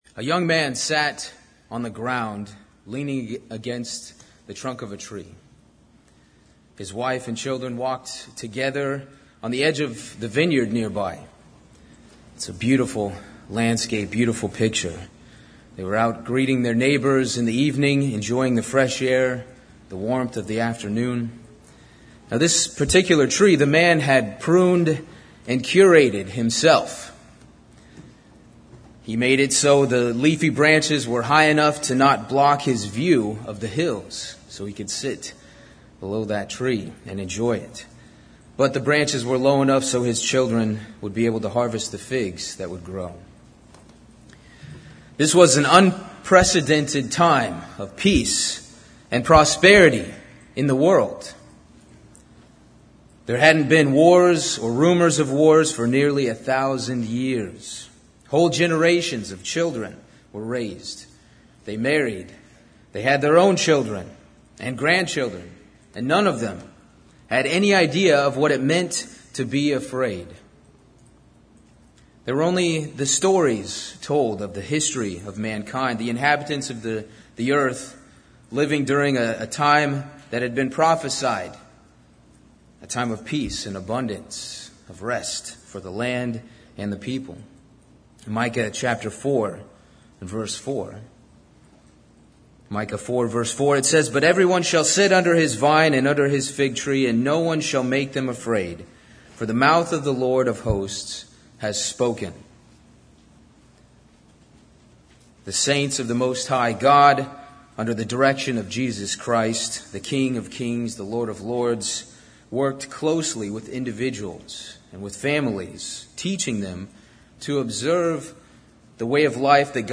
This sermon was given at the Cincinnati, Ohio 2021 Feast site.